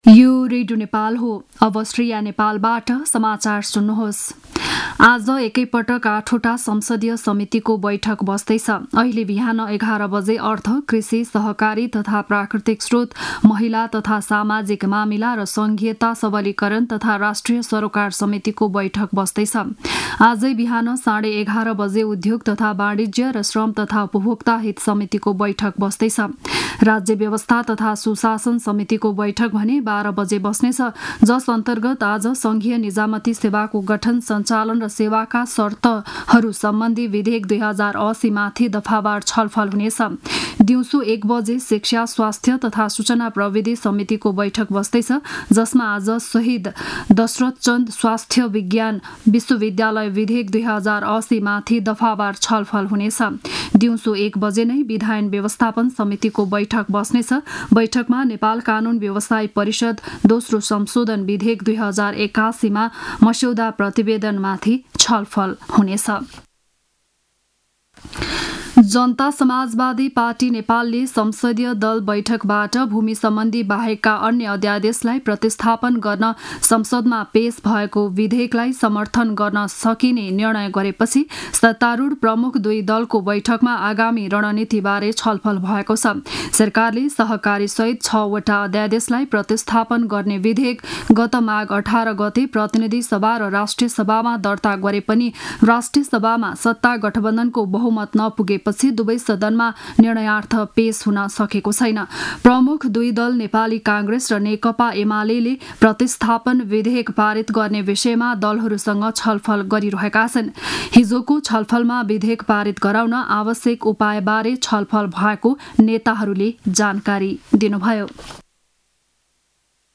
बिहान ११ बजेको नेपाली समाचार : १ फागुन , २०८१
11-am-news-1-5.mp3